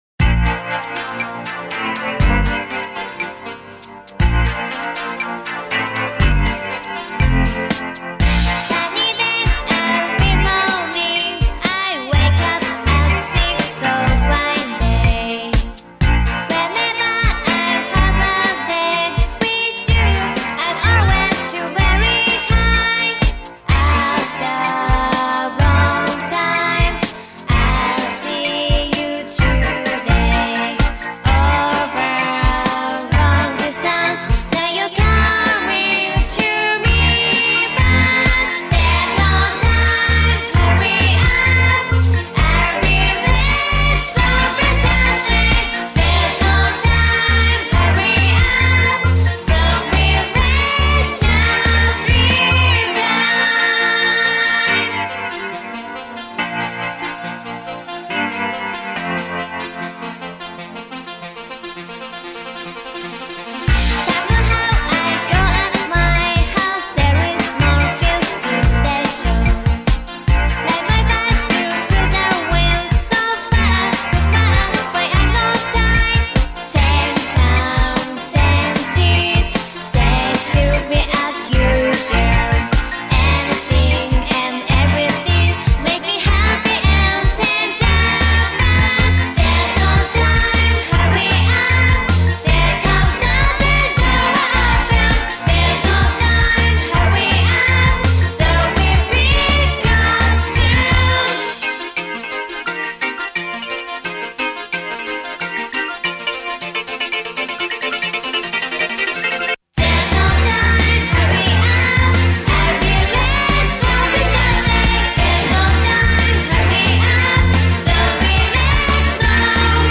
Avant-Garde Electronic Experimental Synth